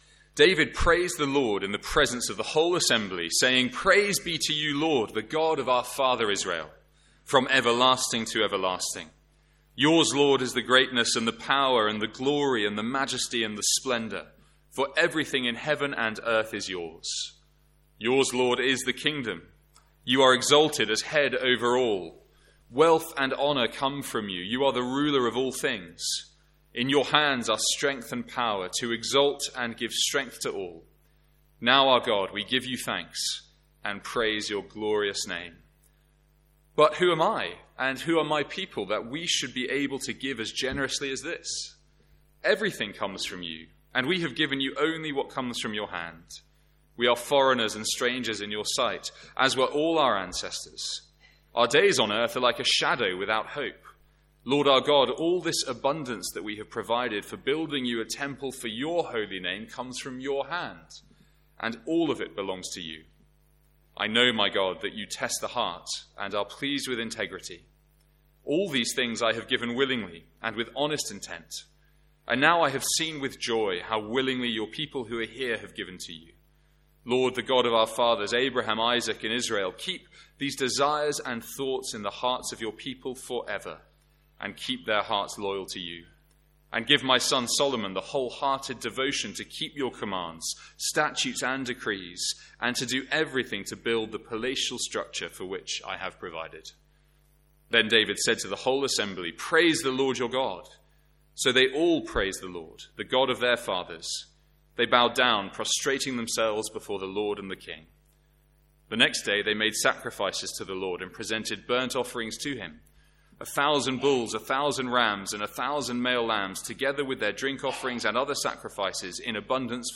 Sermons | St Andrews Free Church
From our morning service on Giving Sunday.